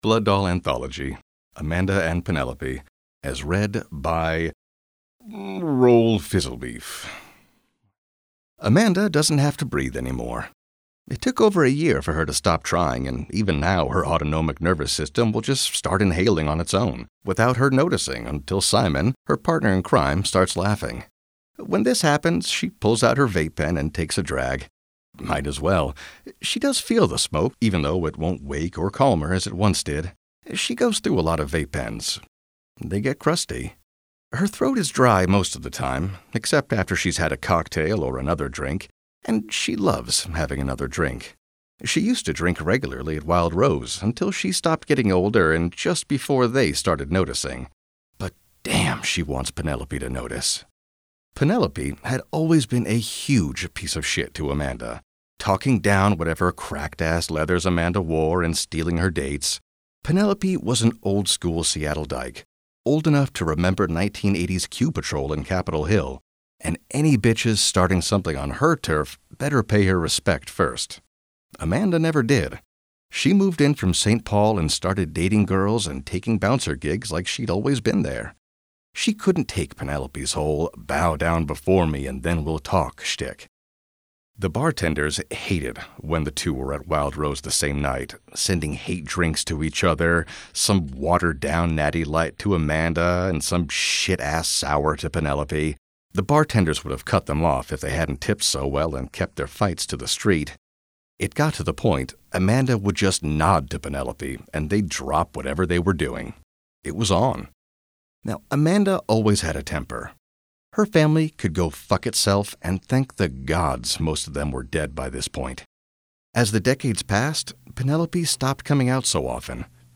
A series of vignettes about the characters in Vampire:The Masquerade: Blood Doll, read by their actors.